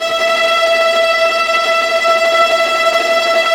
Index of /90_sSampleCDs/Roland LCDP08 Symphony Orchestra/STR_Vas Bow FX/STR_Vas Tremolo